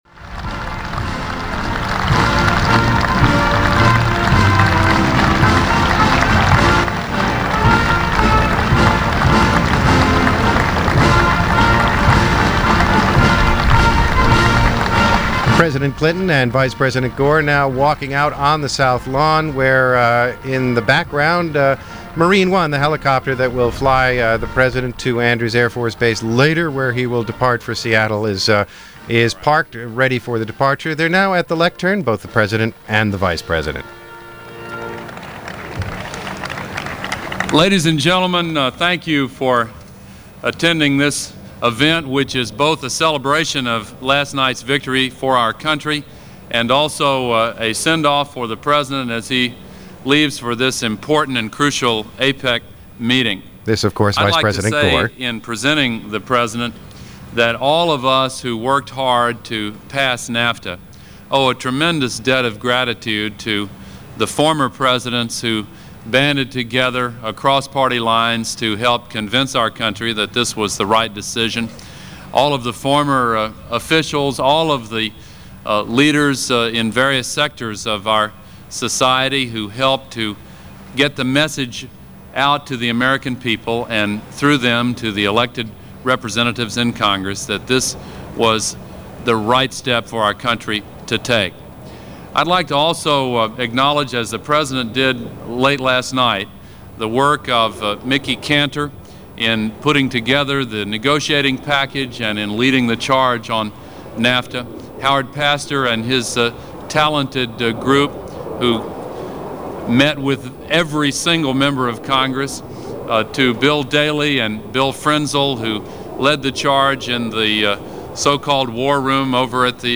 Just prior to his leaving for the first APEC Economic Summit in Seattle, President Clinton offered remarks on the historic vote and passage of NAFTA.
Here are those remarks via a Special Report from NPR on November 18, 1993.